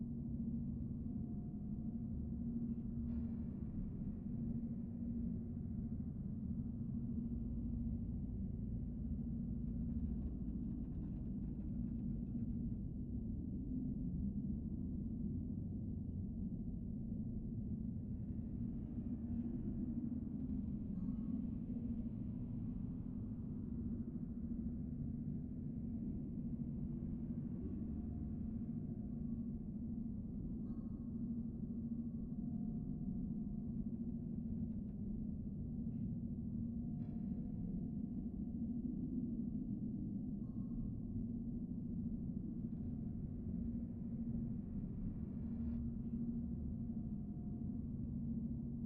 Minecraft Version Minecraft Version snapshot Latest Release | Latest Snapshot snapshot / assets / minecraft / sounds / ambient / nether / warped_forest / ambience.ogg Compare With Compare With Latest Release | Latest Snapshot
ambience.ogg